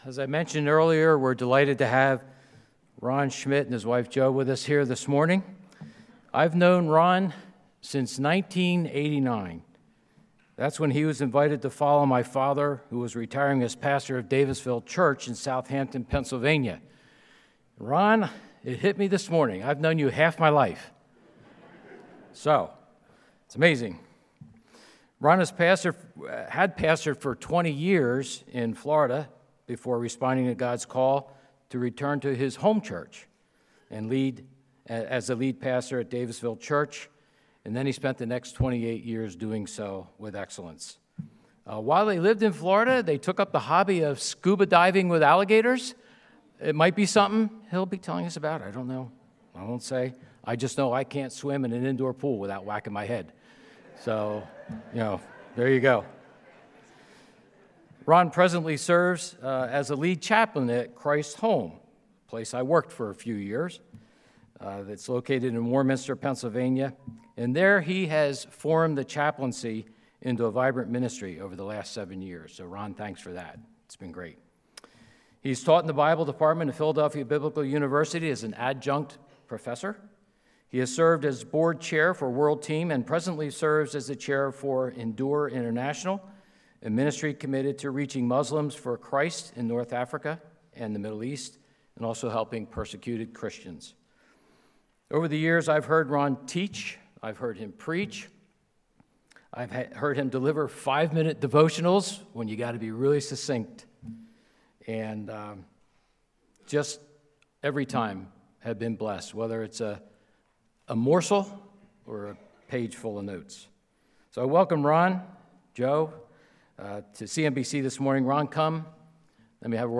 Sermons | Calvary Monument Bible Church
2024 Global Outreach Conference